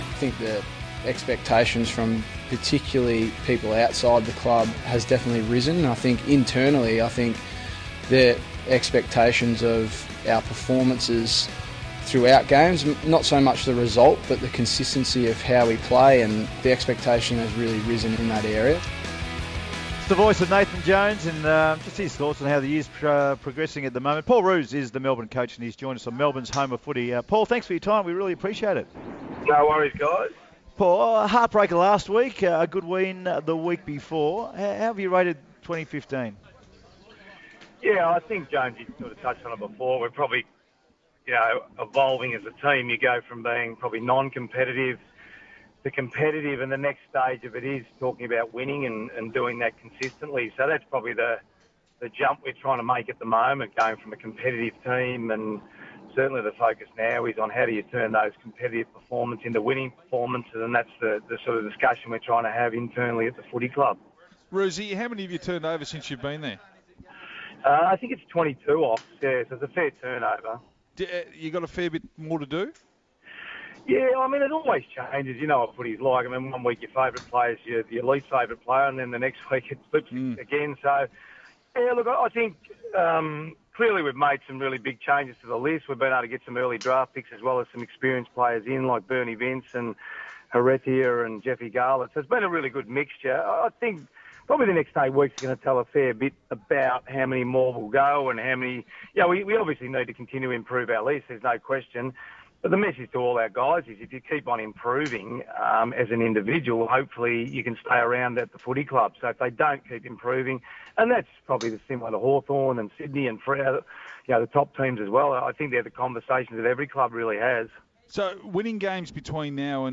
Melbourne coach Paul Roos speaks to The Run Home about Melbourne's improvement this year, their list management strategy for the upcoming off-season, and how we can improve stoppage numbers.